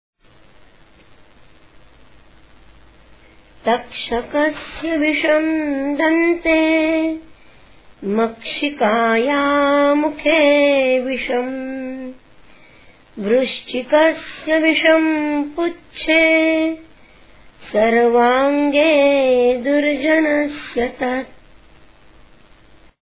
सुभाषित ऎका